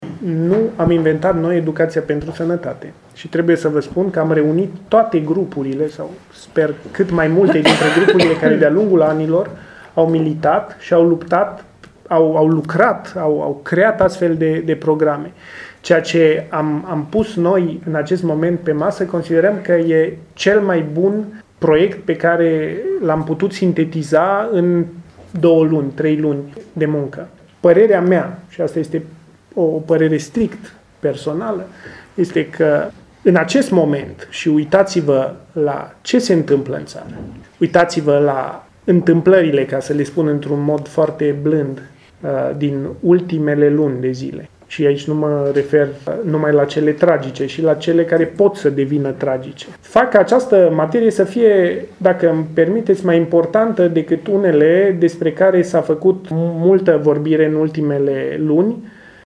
Ministrul Sănătății, Patriciu Achimaș Cadariu: